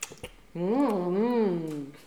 hum-delicieux_01.wav